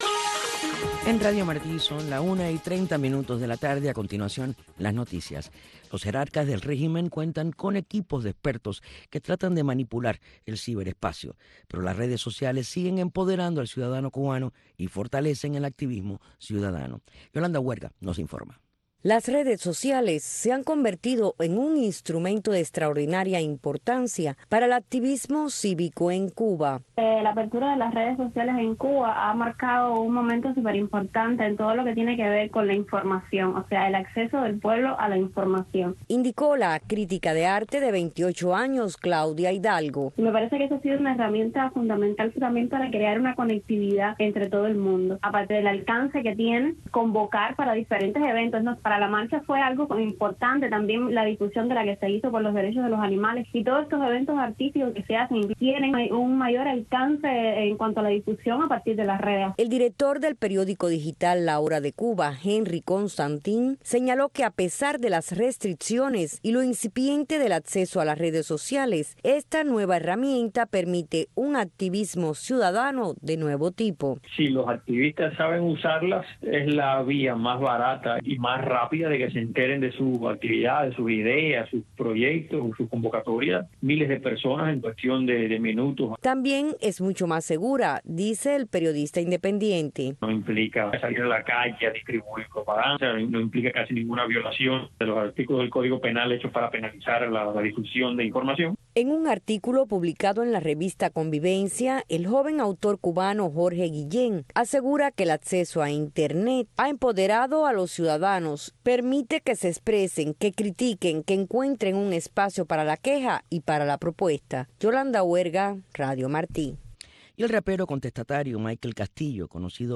“Ventana a Miami”, un programa conducido por el director de la oficina de transmisiones hacia Cuba, Tomás Regalado, te invita a sintonizarnos de lunes a viernes a la 1:30 PM en Radio Martí. “Ventana a Miami” te presenta la historia de los cubanos que se han destacado en el exilio para que tú los conozcas.